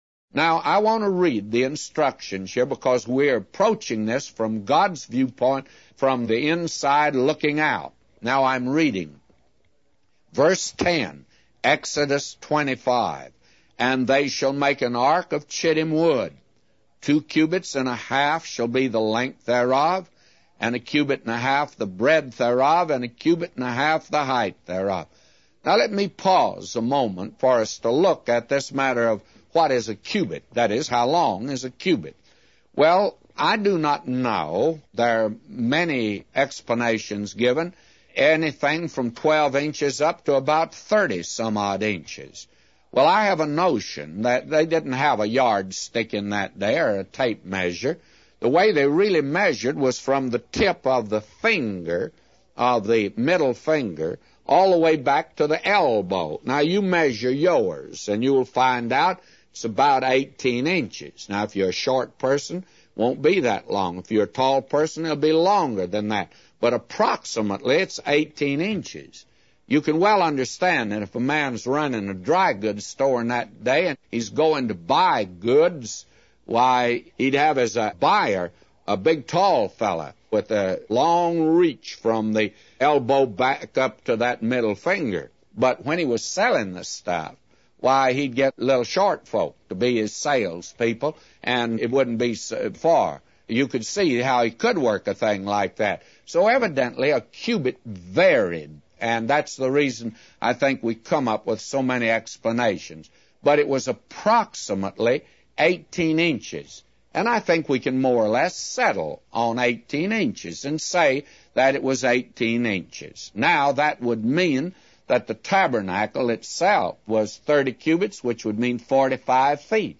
A Commentary By J Vernon MCgee For Exodus 25:10-999